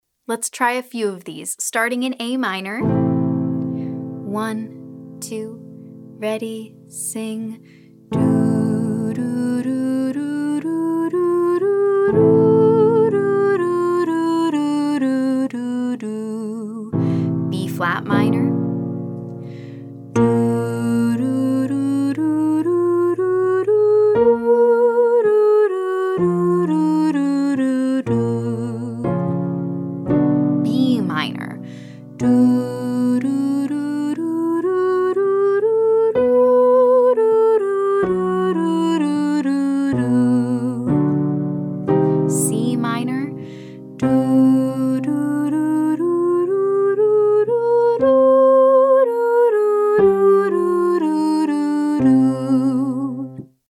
• Minor scale ascending & descending